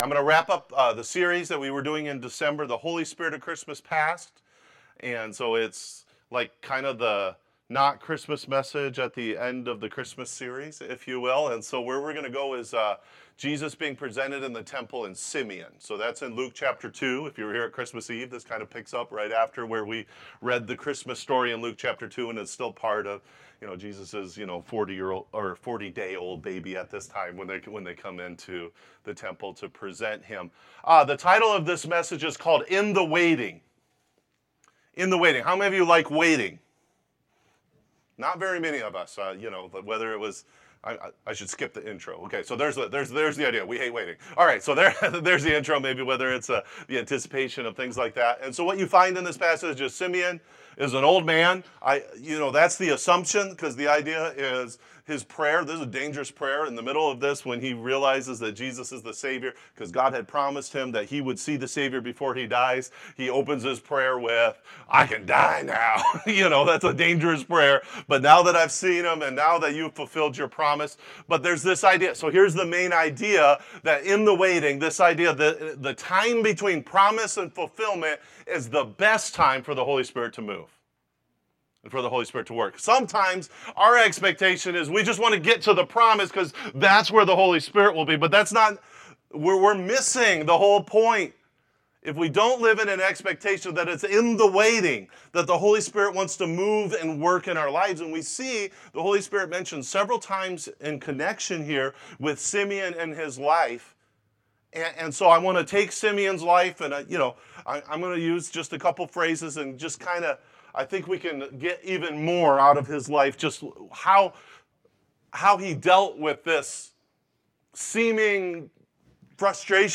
Sermons - Life Worship Center